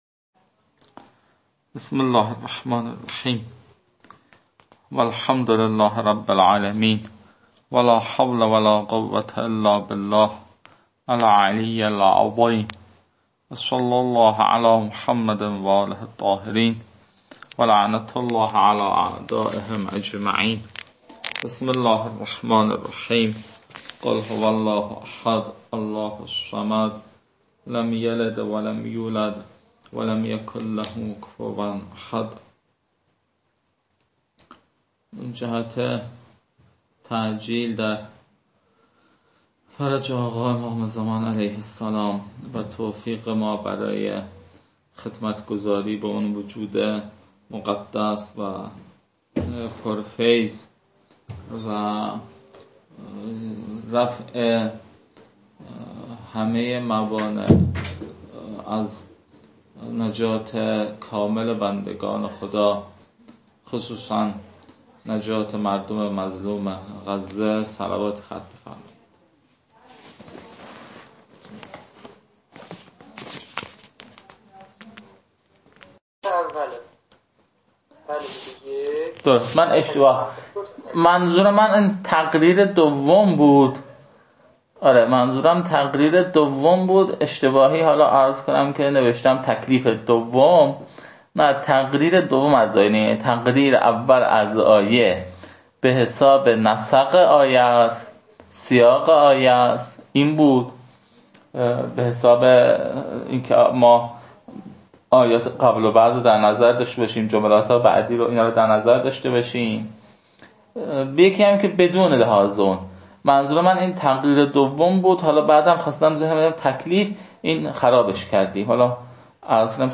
جلسه بیست و هفتم: پرسش و پاسخ